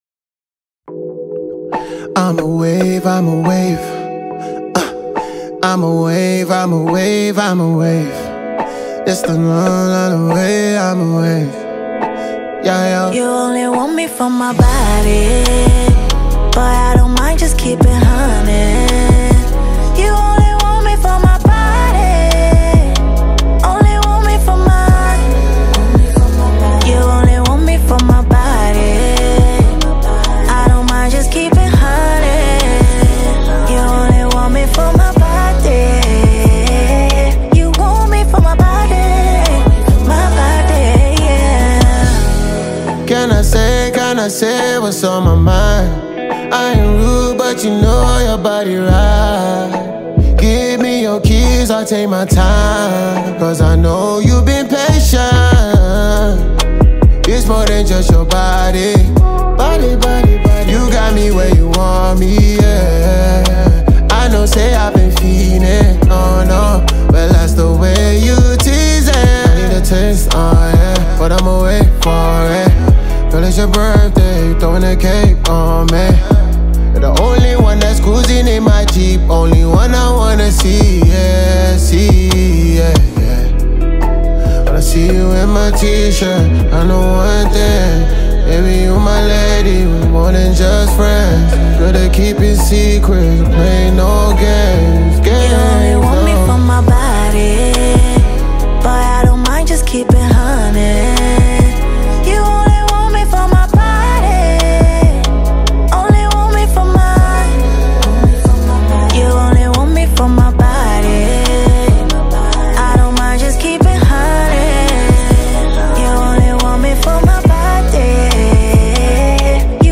love track
R&B joint